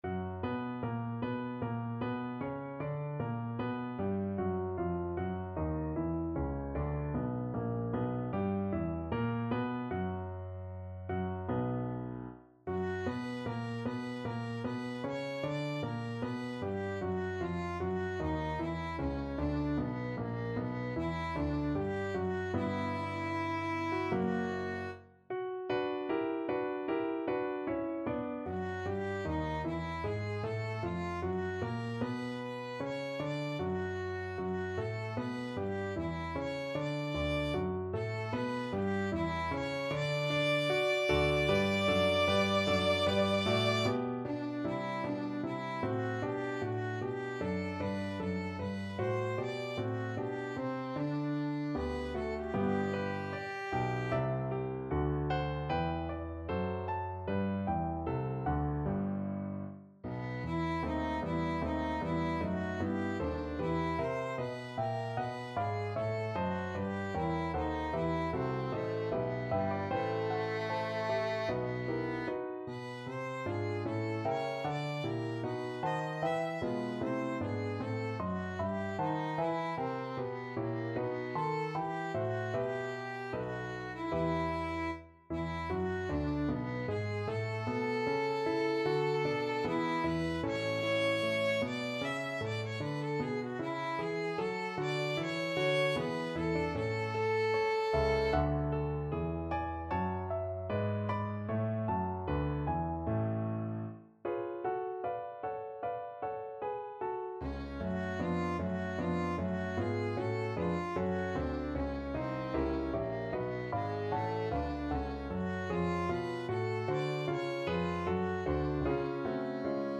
Violin
4/4 (View more 4/4 Music)
B minor (Sounding Pitch) (View more B minor Music for Violin )
Larghetto (=76)
Classical (View more Classical Violin Music)
messiah_comp_vocal_VLN.mp3